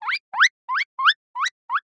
animal
Guinea Pig Squeak 2